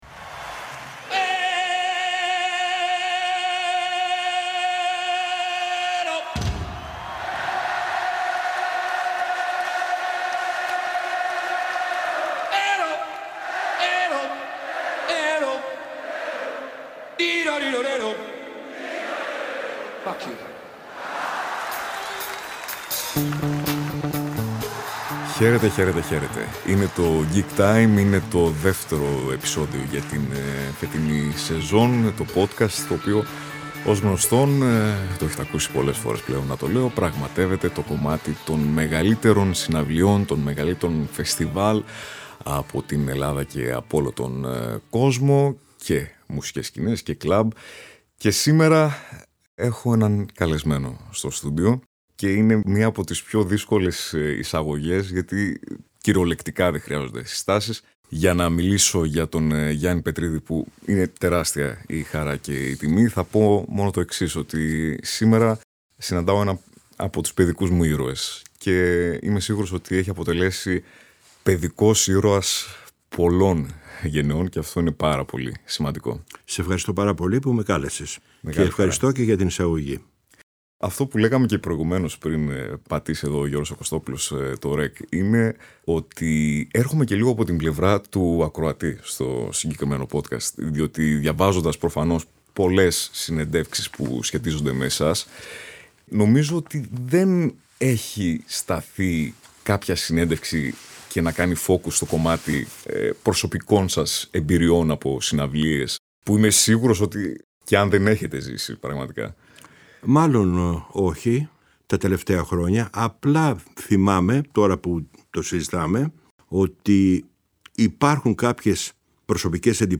Ο Γιάννης Πετρίδης είναι ο πρώτος καλεσμένος για τη δεύτερη σεζόν του Gig time και οι συστάσεις είναι κυριολεκτικά περιττές.